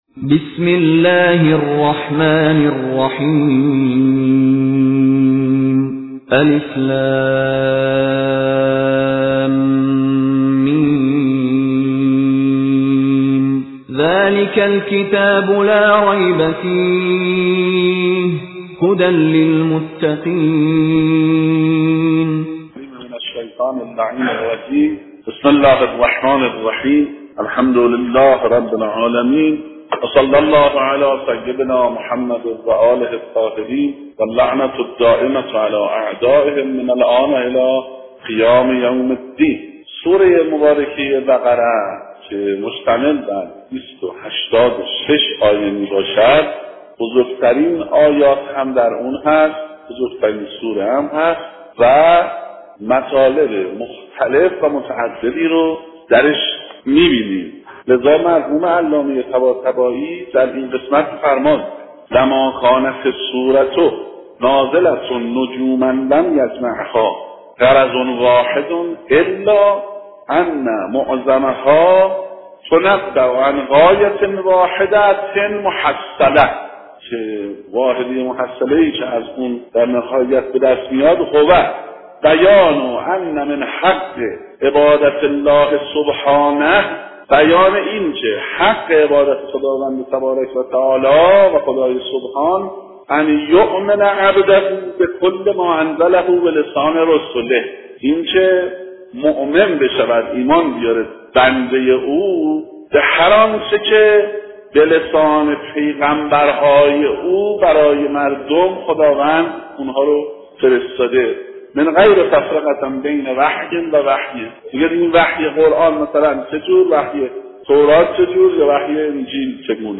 حوزه علمیه اصفهان - مدرسه صدر بازار